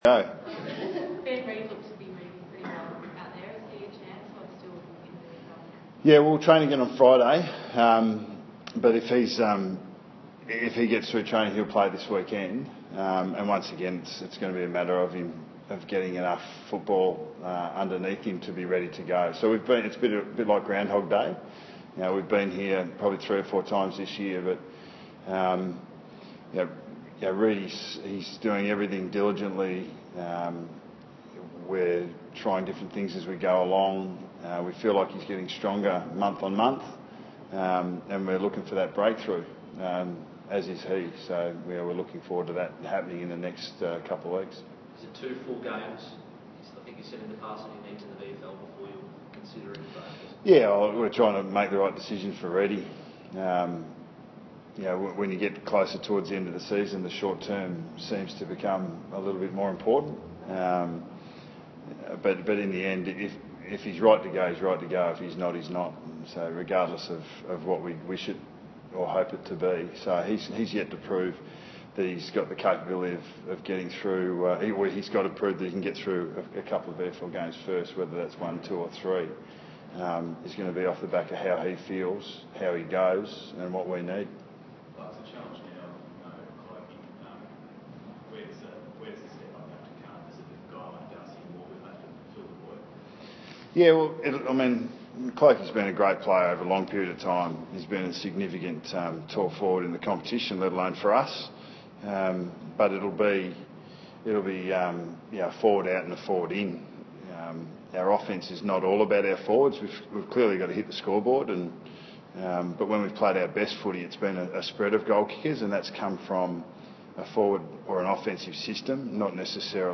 Listen to Nathan Buckley's mid-week press conference at the Westpac Centre on Wednesday 22 July 2015.